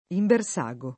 [ imber S#g o ]